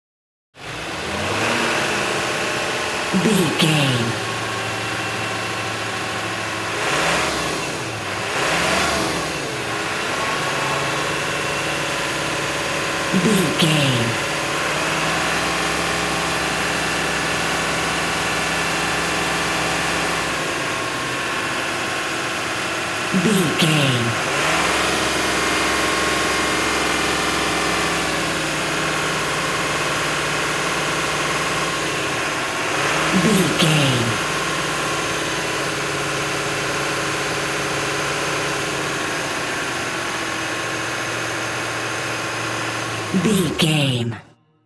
Ambulance Ext Diesel Engine Accelerate
Sound Effects
urban
chaotic
emergency